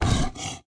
Download Npc Raccoon Run sound effect for video, games and apps.
Npc Raccoon Run Sound Effect
npc-raccoon-run-3.mp3